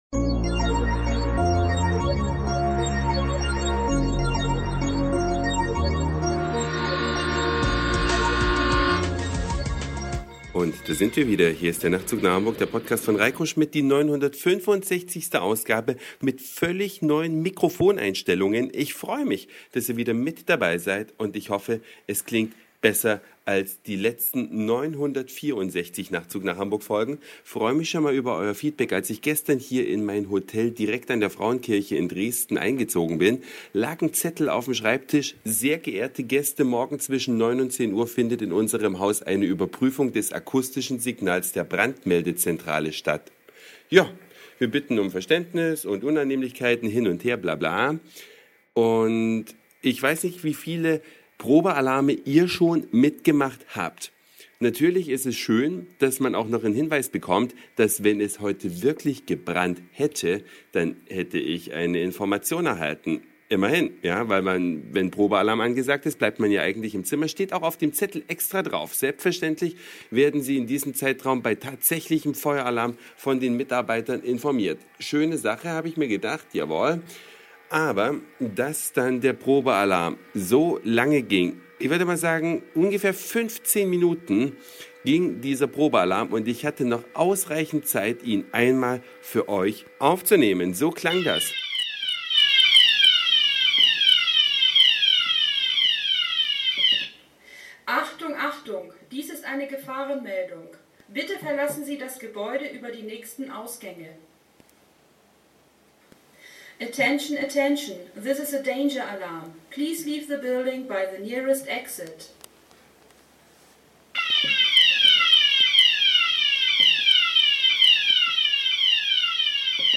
Eine Reise durch die Vielfalt aus Satire, Informationen, Soundseeing und Audioblog.